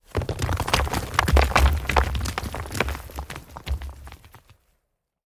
Rock_Tumble_Down_Debris_Long_06.ogg